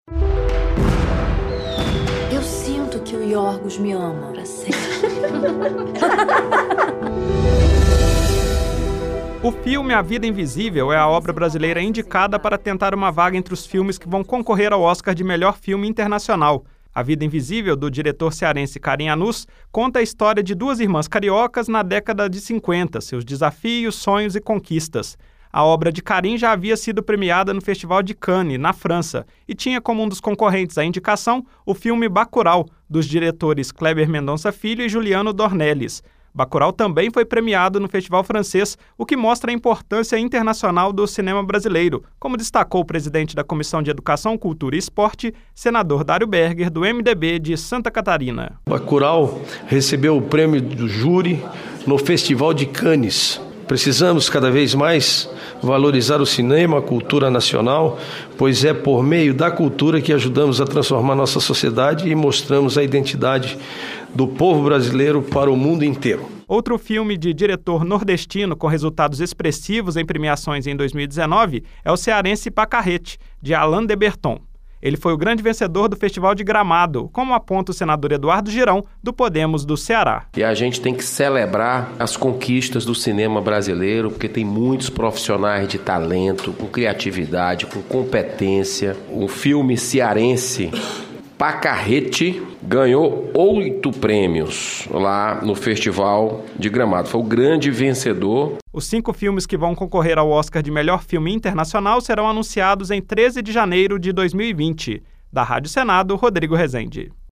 O senador Dario Berger, do MDB de Santa Catarina, presidente da Comissão de Educação, Cultura e Esporte, afirmou que o cinema do país mostra ao mundo a identidade do povo brasileiro. A reportagem